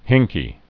(hĭngkē)